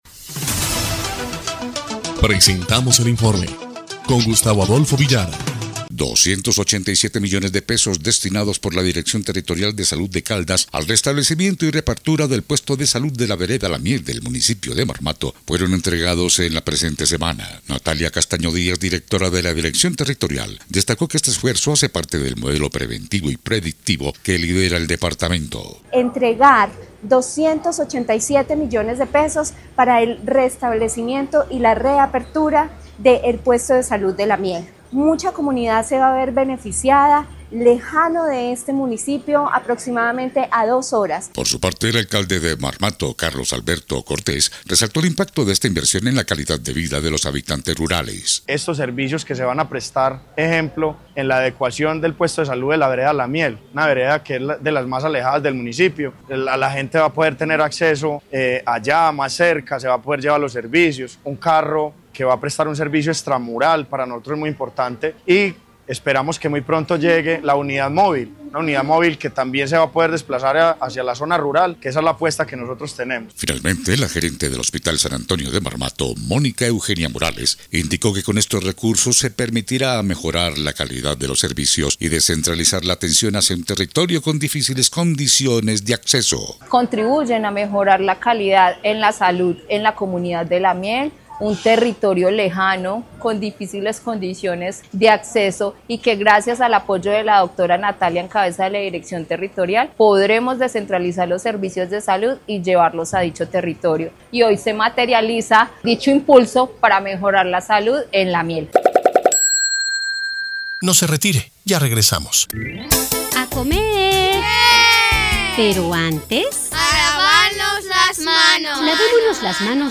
EL INFORME 1° Clip de Noticias del 13 de noviembre de 2025